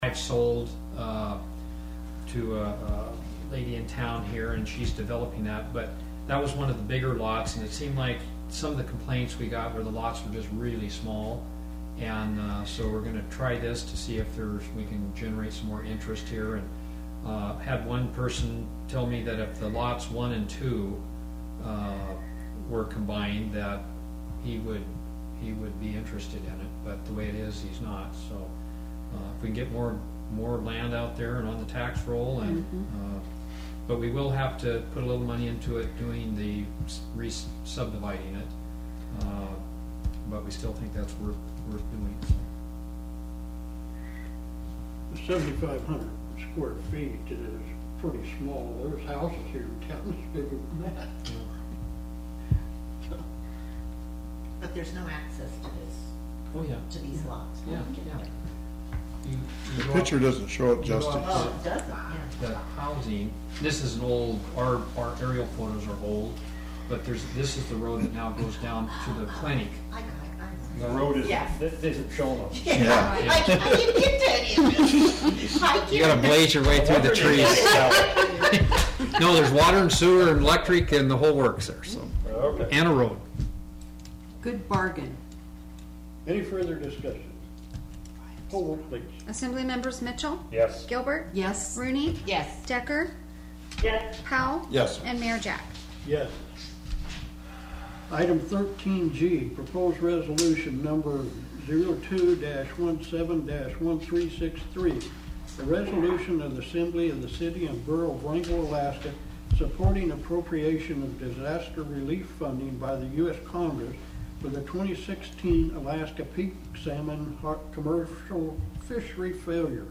The Wrangell Borough Assembly met on Wednesday February 15, 2017 for a regular assembly meeting.